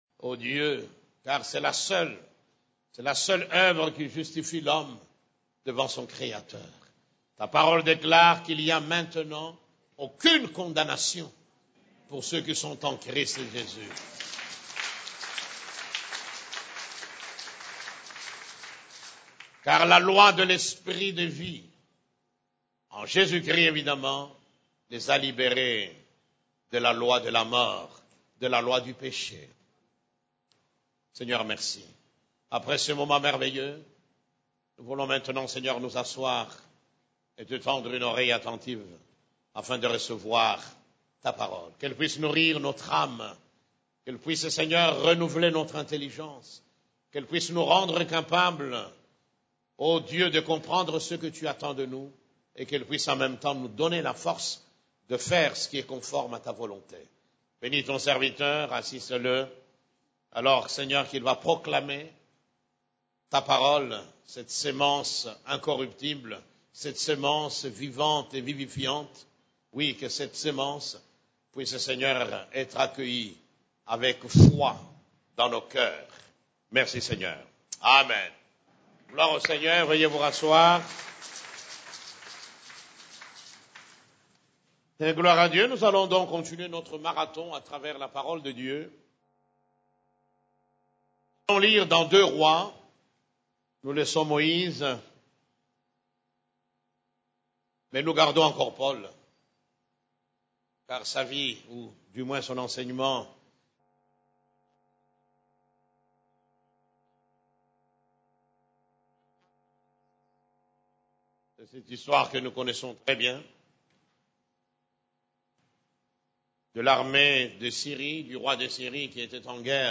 CEF la Borne, Culte du Dimanche, Voir l'invisible afin d'aller loin (10)